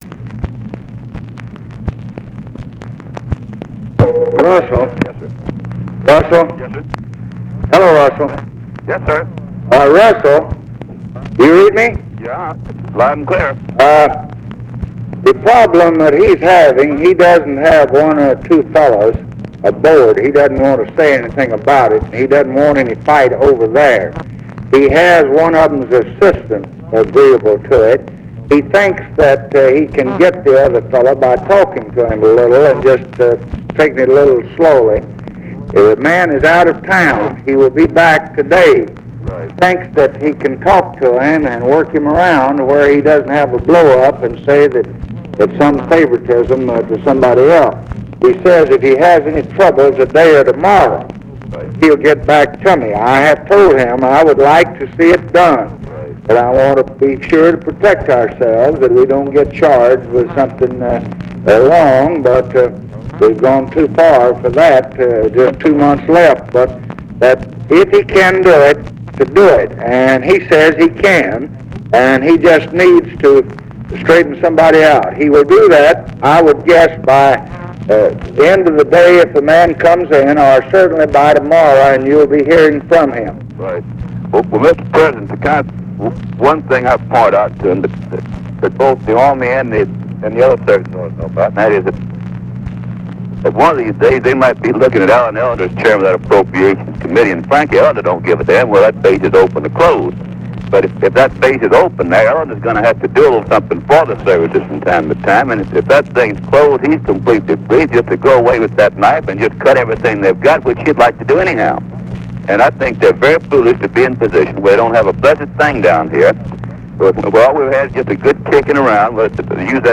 Conversation with RUSSELL LONG, October 22, 1968
Secret White House Tapes